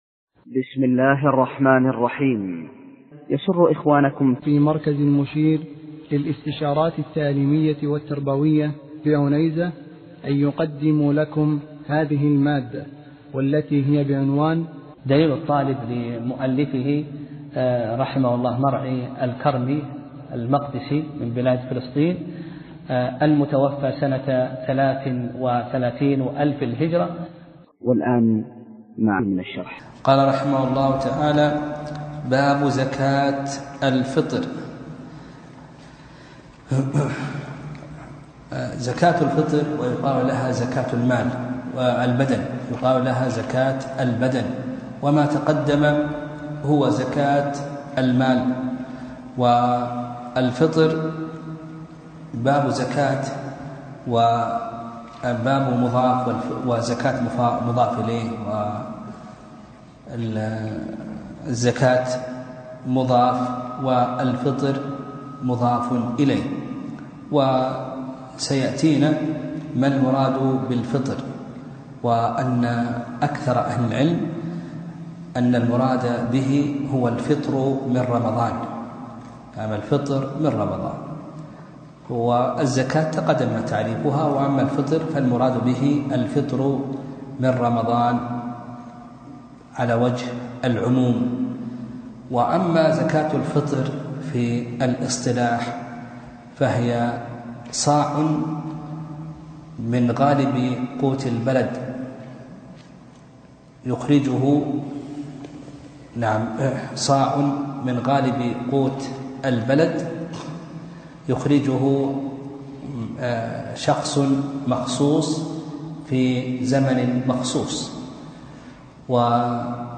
درس (8) : كتاب الزكاة: باب زكاة الفطر